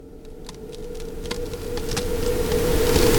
Buildup.mp3